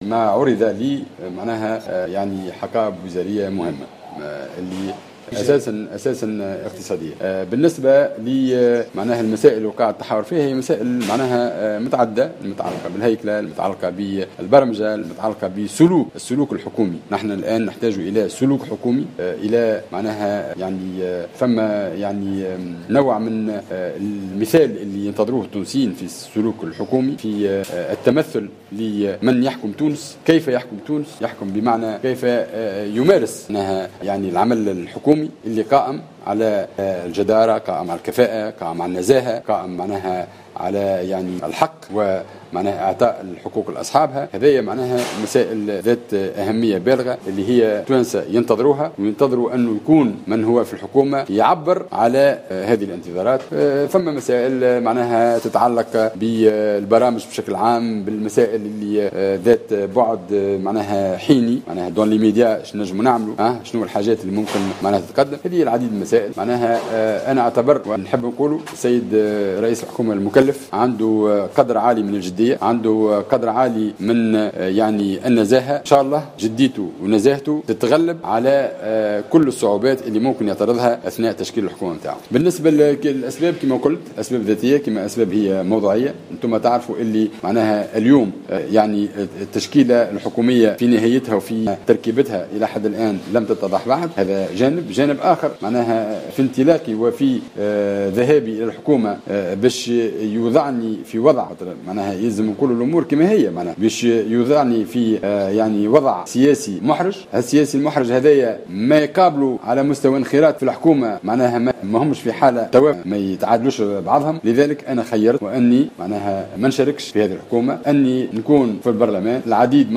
Lors d'une conférence de presse organisée ce mercredi 17 août 2016, le leader au Front Populaire, Monji Rahoui a déclaré qu’il ne compte pas faire partie du gouvernement d’union nationale de Youssef Chahed.